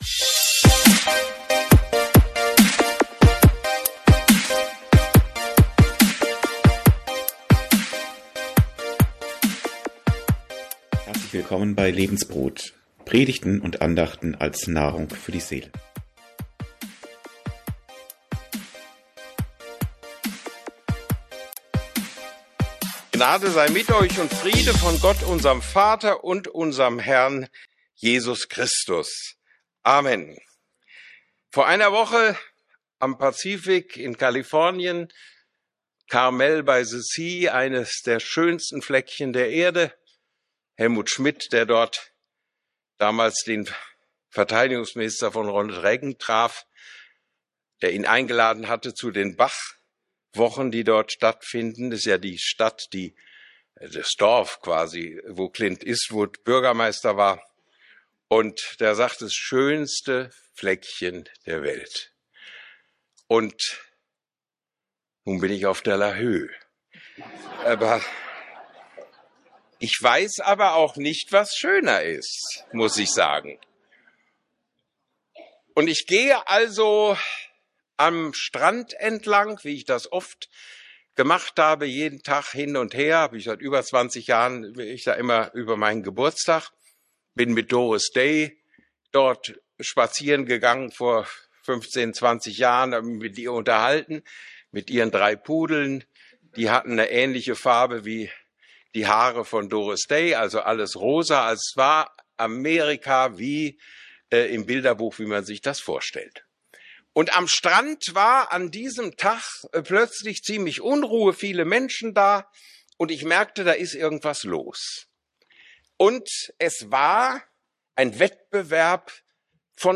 Predigten u. Andachten (Live und Studioaufnahmen ERF)
Predigt von Peter Hahne - Lahö 24.11.24